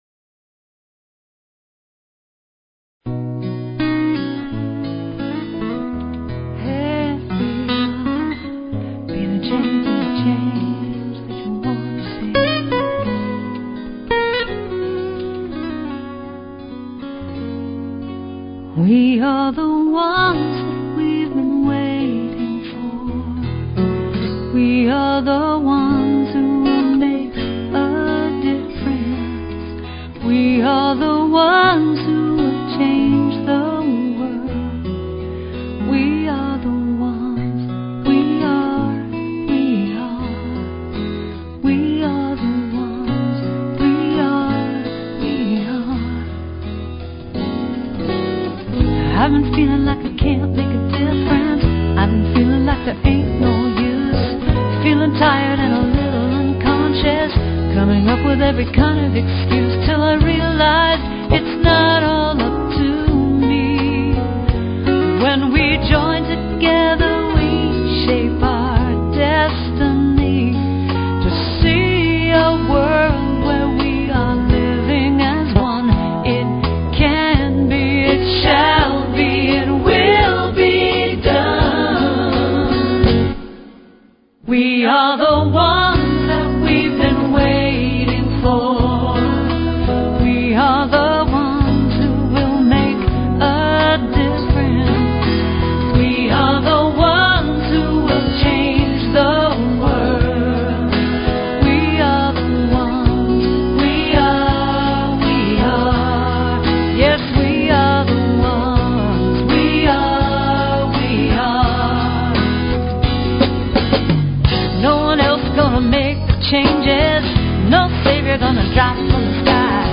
Talk Show Episode, Audio Podcast, Tallkats Psychic 101 and Courtesy of BBS Radio on , show guests , about , categorized as